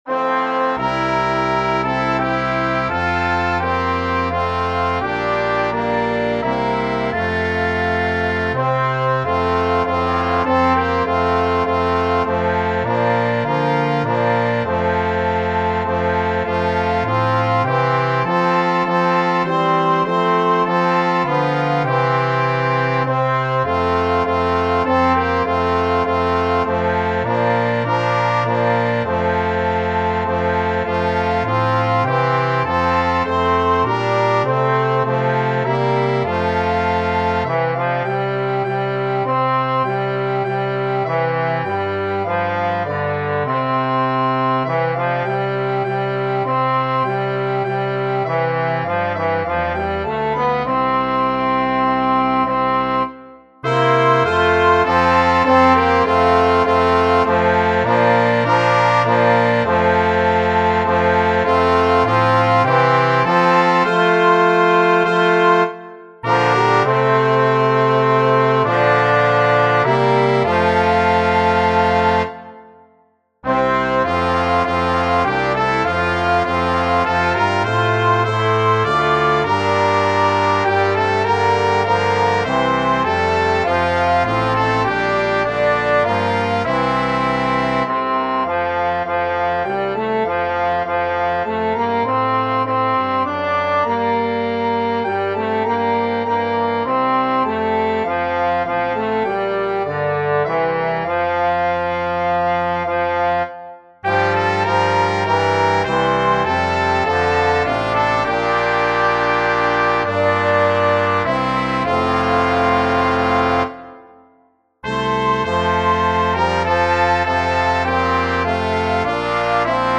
5 parts for multiple brass & woodwinds